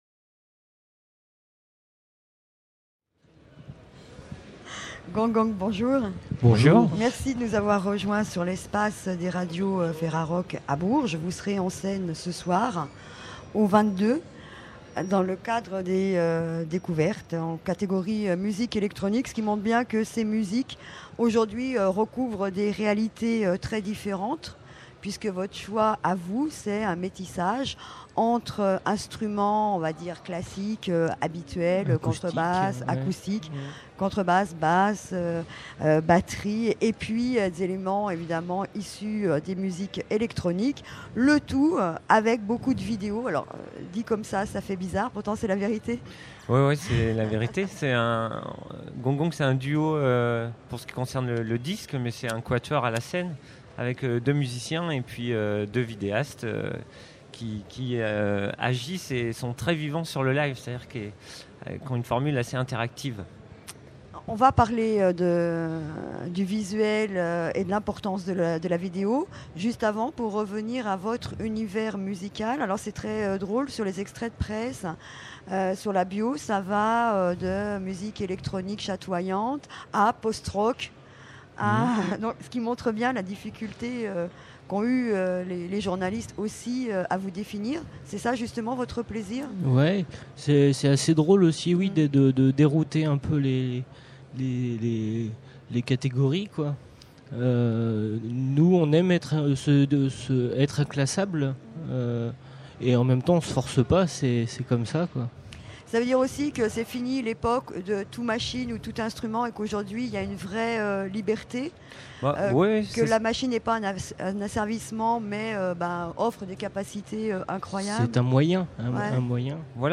Gong Gong Festival du Printemps de Bourges 2006 : 40 Interviews à écouter !